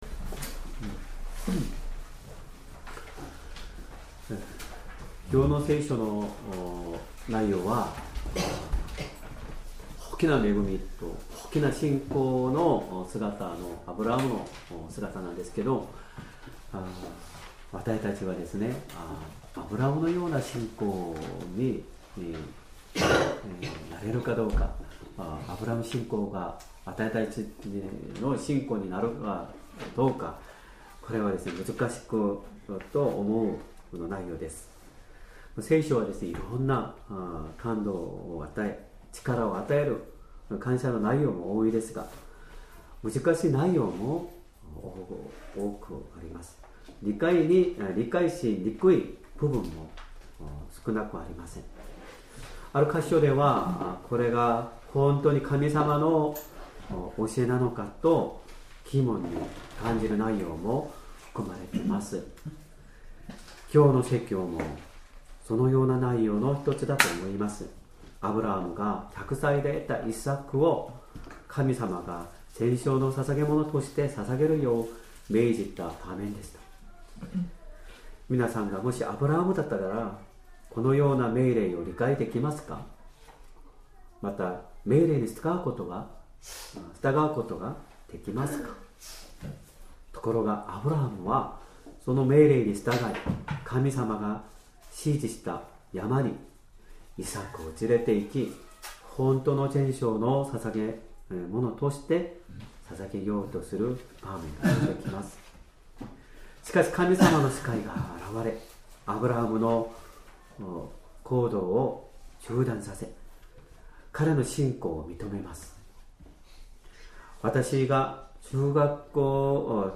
Sermon
2025年5月25日 主日礼拝 説教 「大切なものを捧げるアブラハムの信仰 」 聖書 創世記 22章 1-19節 22:1 これらの出来事の後、神がアブラハムを試練にあわせられた。